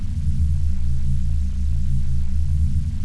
hcsambient2.wav